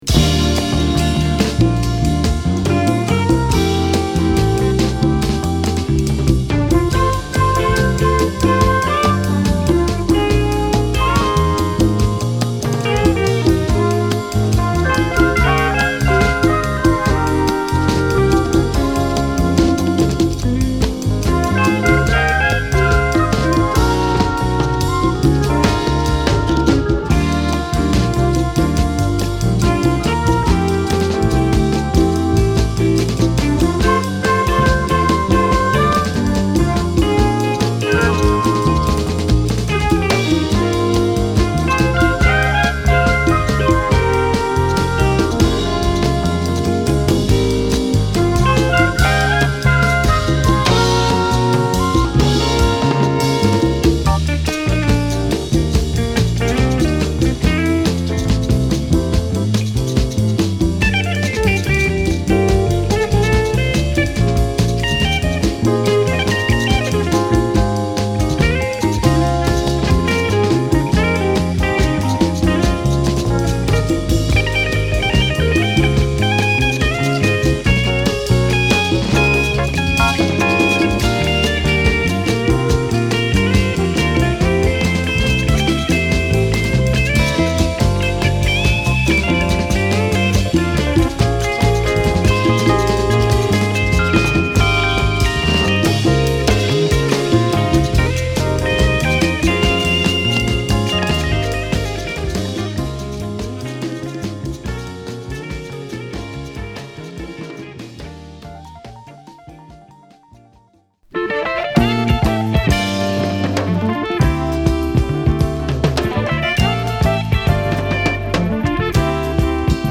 グルーヴィーなベースラインから始まり、エレピやギターが心地良い爽快なA3